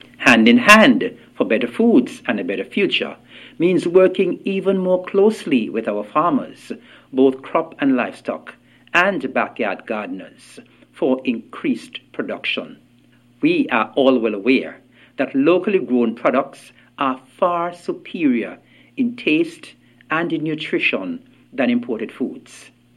In his address to mark World Food Day 2025, observed globally on October 16th, Mr. Evelyn underscored the importance of collective effort under this year’s theme “Hand in Hand for Better Foods and a Better Future.”